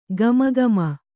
♪ gama gama